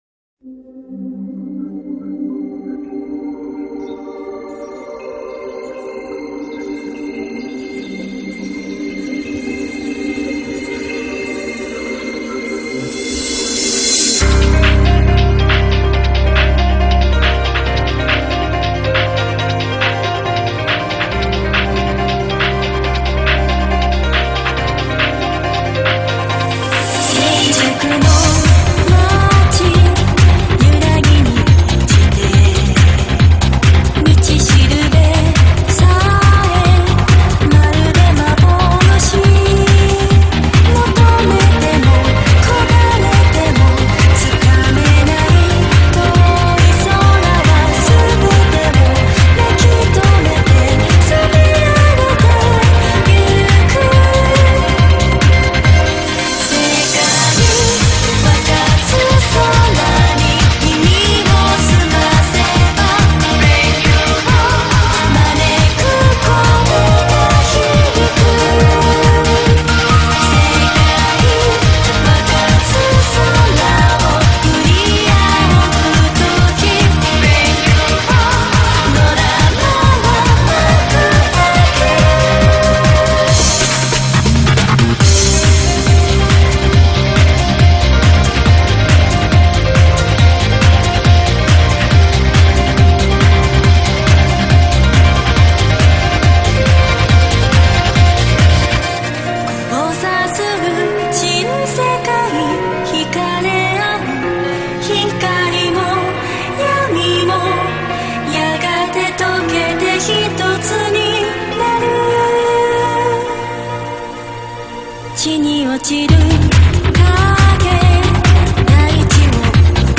BGM